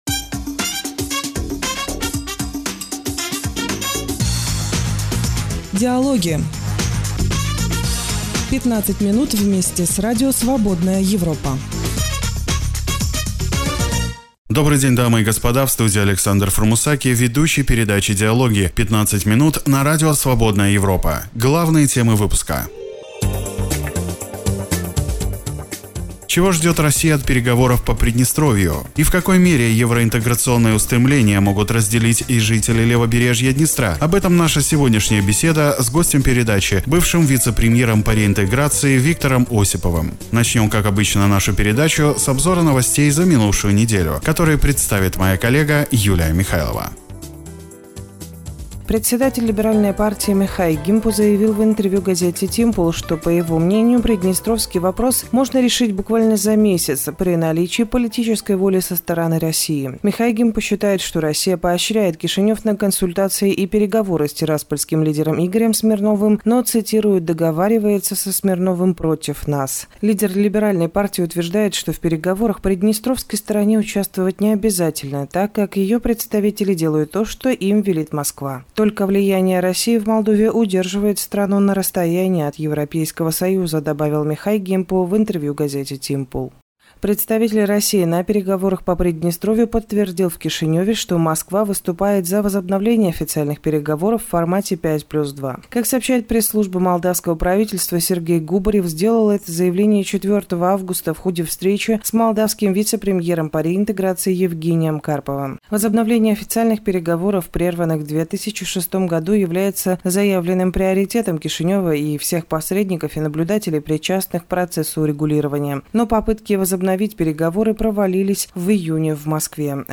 «Гостеприимство» по-южноосетински: бессилие геополитического «заложника» - репортаж из Южной Осетии.